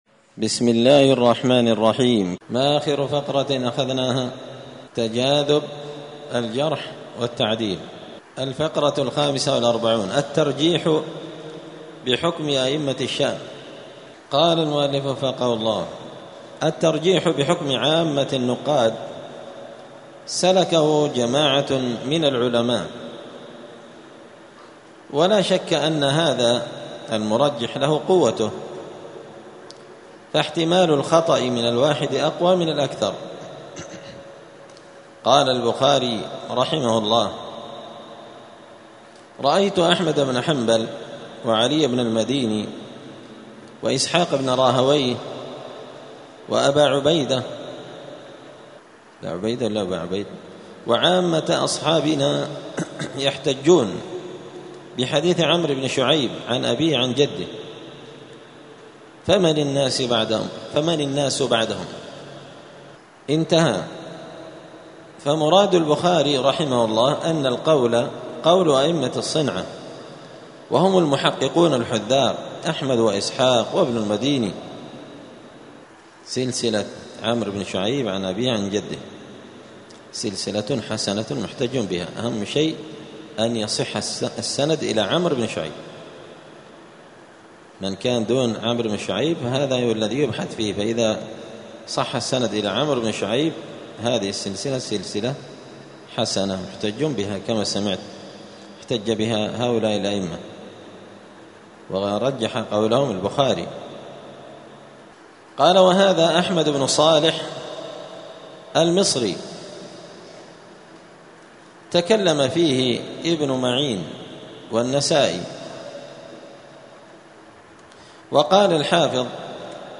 *الدرس السادس والخمسون (56) تابع لباب تجاذب الجرح والتعديل.*